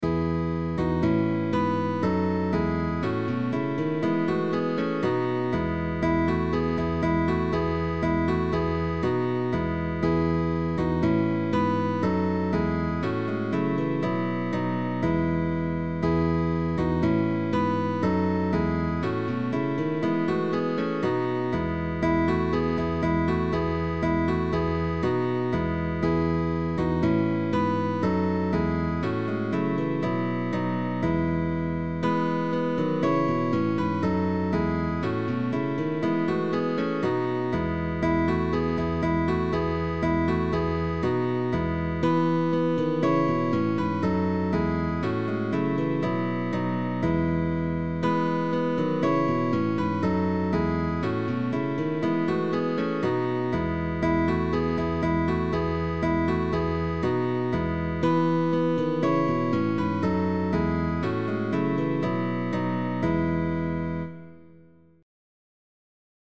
0,95 € GUITAR QUARTET Right Hand
Guitar Quarters
Tag: Early music